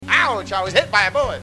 Hit by a bullet